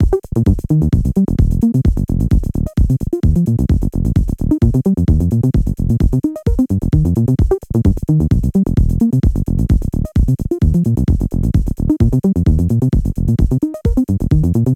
One 16-step sequence with the following adjustments:
Scale: Pentatonic Minor
BPM: 130
Velocity +/- 33%
Octave + 22%
I amended a bunch of the notes to play Legato so they slide in that 303 way that we all love.